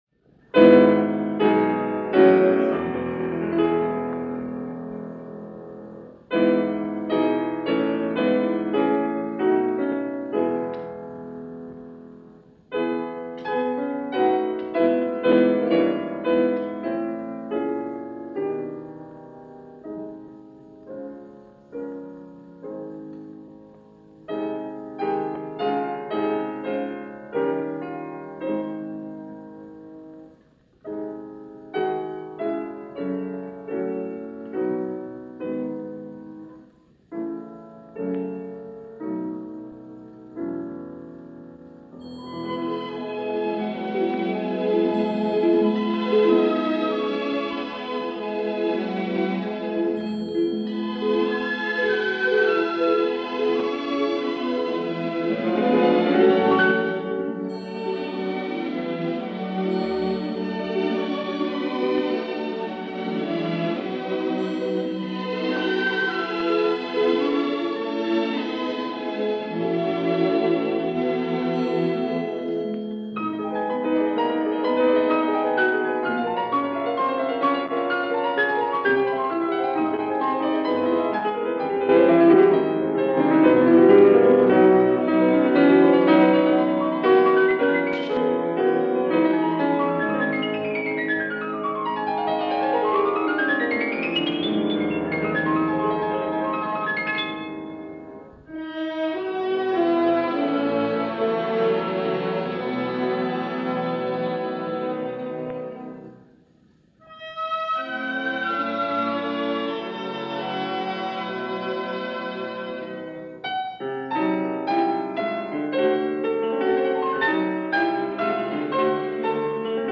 – Reynaldo Hahn – Piano Concerto – Jean Doyen, Piano – French Radio Orchestra, Eugène Bigot, cond.
Hahn-Piano-Concerto-1952.mp3